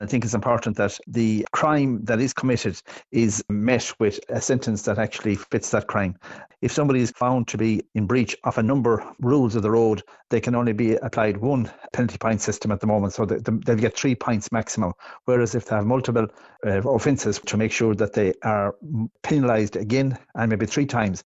Minister Canney says the current legislation is too soft: